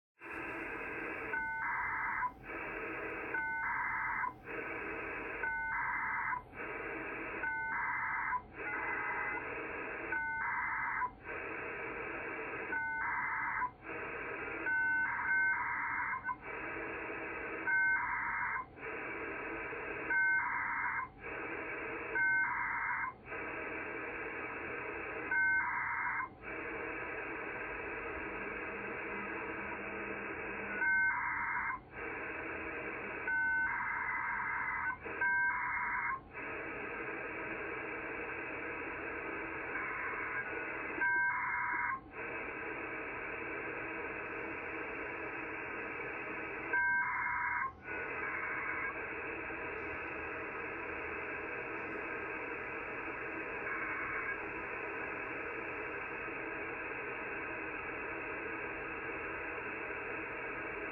１分間のほとんど誰かがアップリンクしまくってます。
最後の方がちょっと静かなのは、 IO-117 がテレメトリーを送信し続ける時間になったからです。
この録音 はある平日の午後、IO-117 のアップリンク周波数を１分間だけスマホで録音したものです。